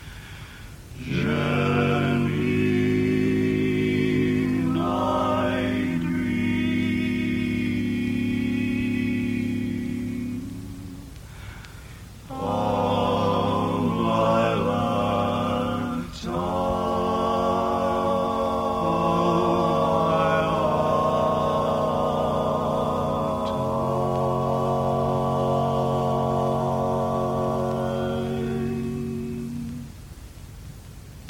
Key written in: G♭ Major
How many parts: 4
Type: Barbershop
All Parts mix: